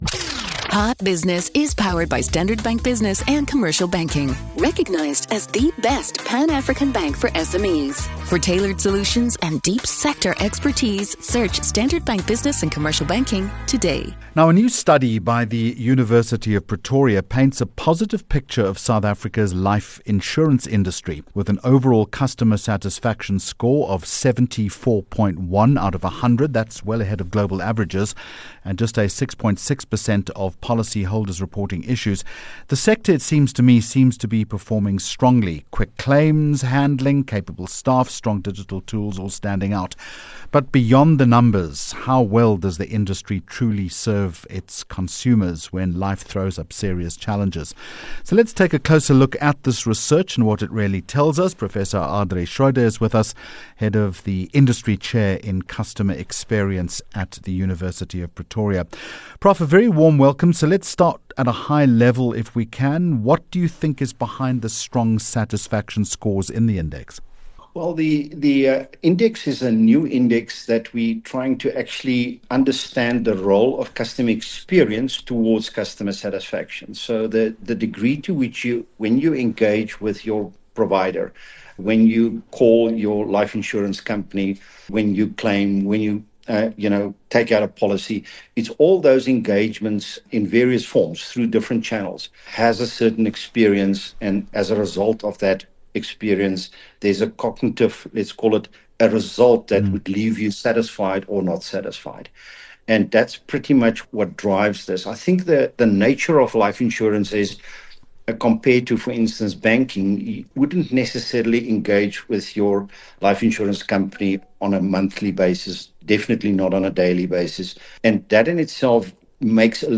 16 Jul Hot Business Interview